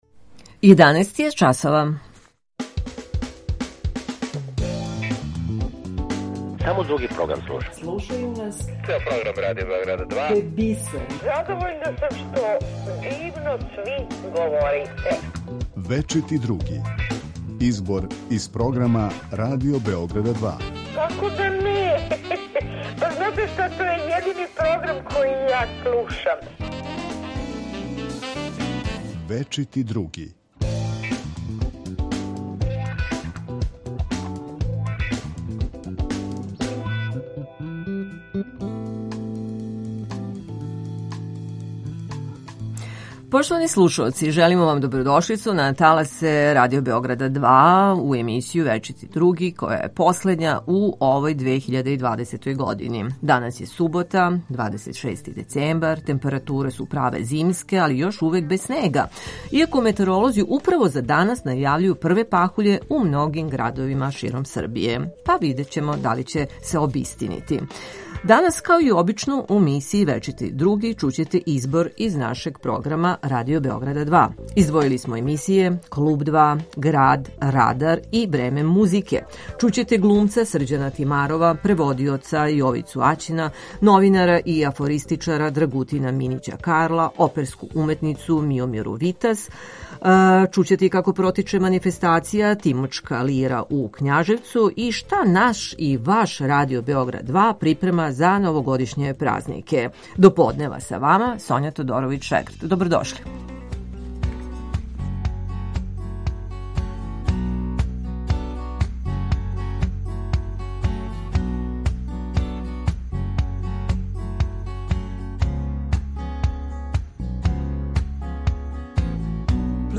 У емисији Вечити други чућете најзанимљивије делове из програма Радио Београд 2.